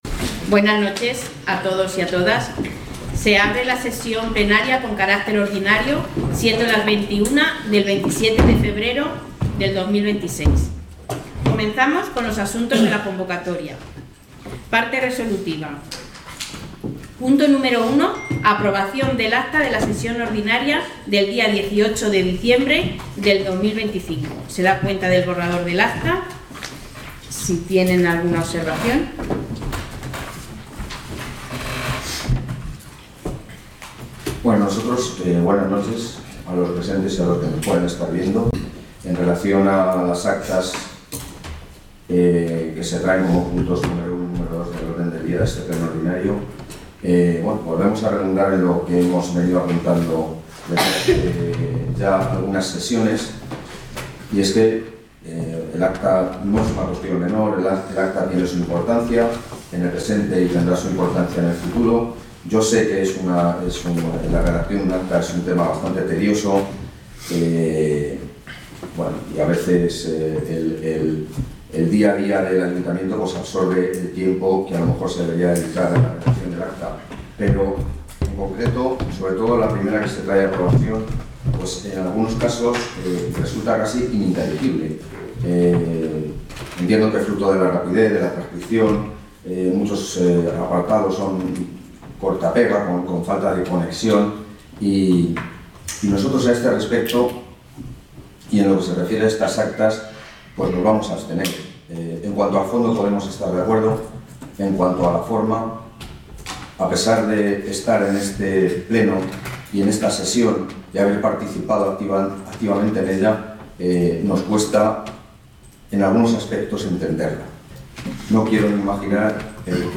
Por Resolución de Alcaldía queda convocada SESIÓN ORDINARIA del Pleno, que tendrá lugar el próximo día 27 de febrero de 2026 a las 21:00 horas, en el Salón de Actos del Ayuntamiento, con el siguiente orden del día.